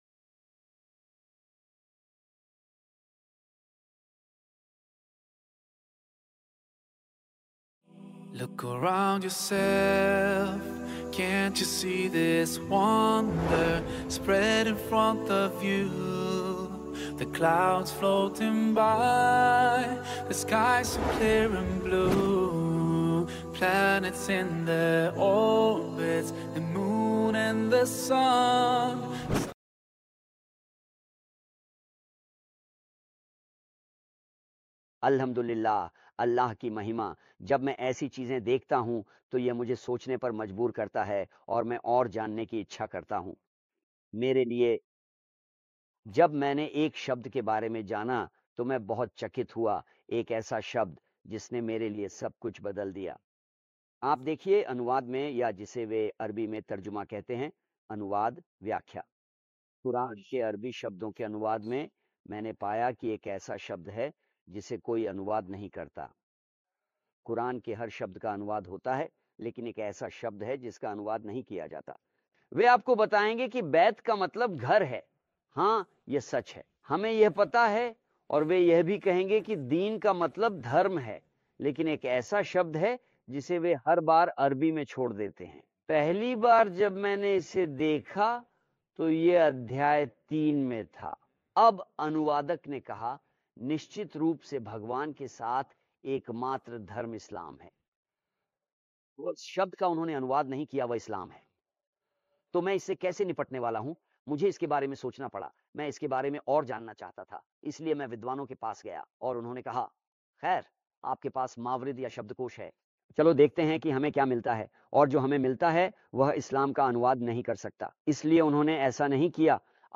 जो जॉर्डन के सुंदर दृश्यों और ऐतिहासिक स्थलों की पृष्ठभूमि में फिल्माई गई है। इस एपिसोड में वह इस्लाम का अर्थ बताते हैं।